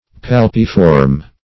Search Result for " palpiform" : The Collaborative International Dictionary of English v.0.48: Palpiform \Pal"pi*form\, a. [Palpus + -form: cf. F. palpiforme.]